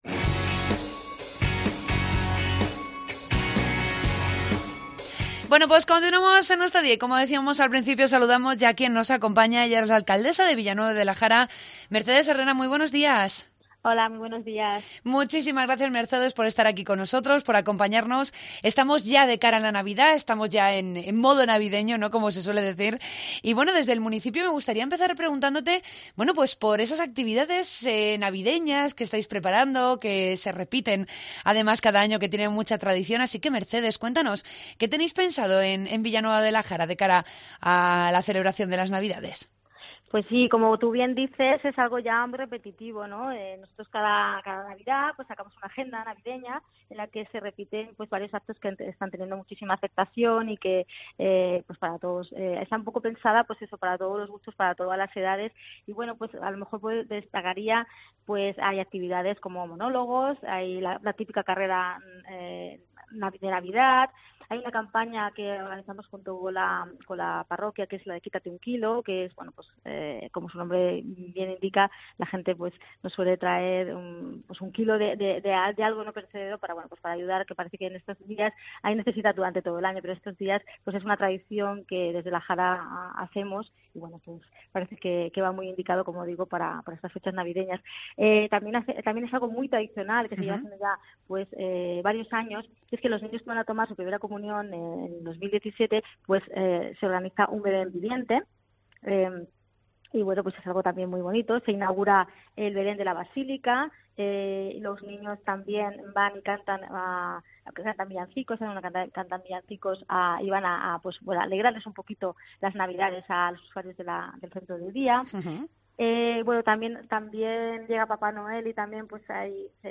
Entrevista a la alcaldesa de Villanueva de la Jara, Mercedes Herreras.